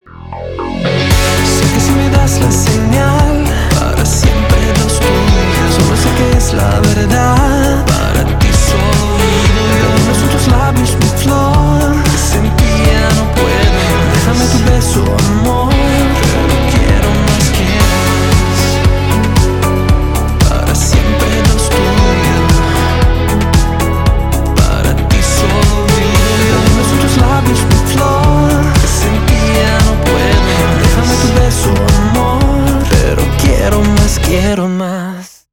• Качество: 320 kbps, Stereo
Поп Музыка
латинские